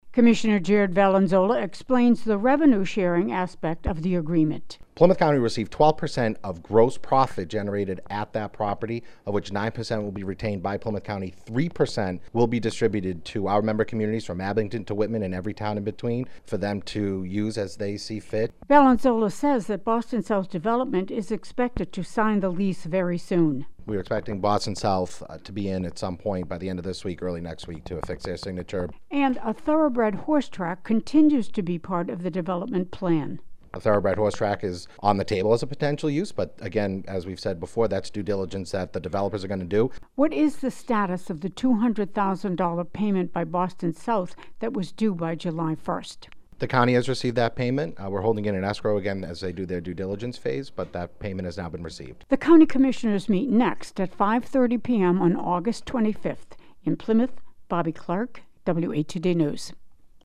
Commissioner Jared Valanzola explains the revenue sharing aspect of the agreement: